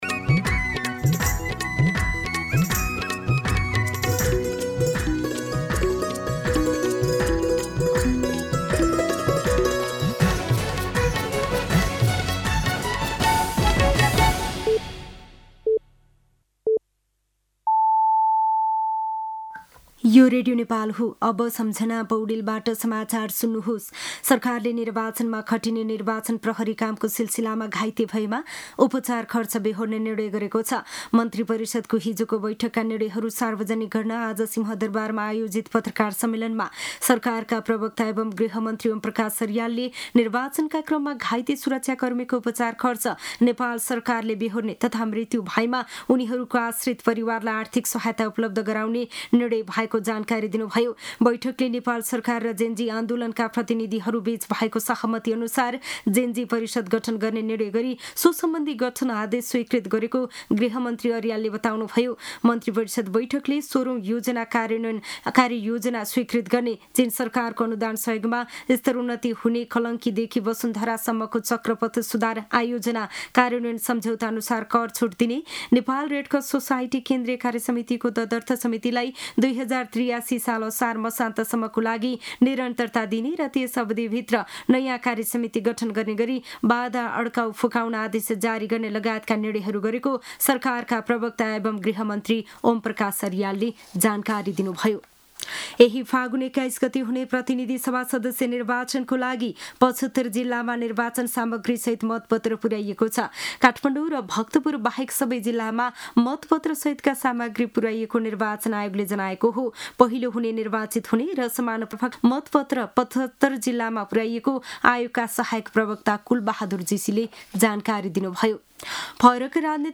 दिउँसो ४ बजेको नेपाली समाचार : १३ फागुन , २०८२